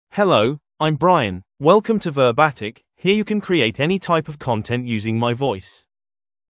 MaleEnglish (United Kingdom)
Brian is a male AI voice for English (United Kingdom).
Voice sample
Listen to Brian's male English voice.
Brian delivers clear pronunciation with authentic United Kingdom English intonation, making your content sound professionally produced.